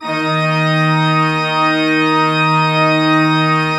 Index of /90_sSampleCDs/Propeller Island - Cathedral Organ/Partition F/PED.V.WERK M